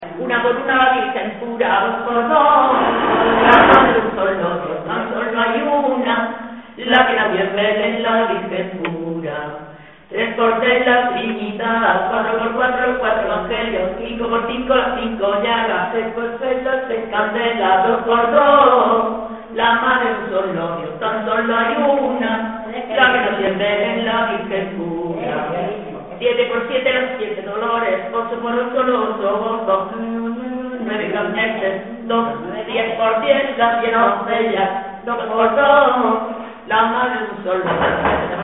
Materia / geográfico / evento: Villancicos Icono con lupa
Zafarraya (Granada) Icono con lupa
Secciones - Biblioteca de Voces - Cultura oral